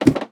ladder3.ogg